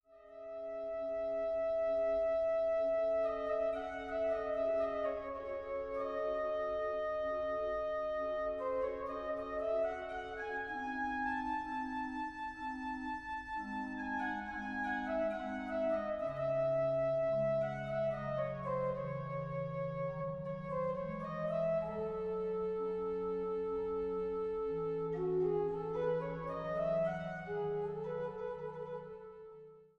an der größten historischen Orgel des Rheinlandes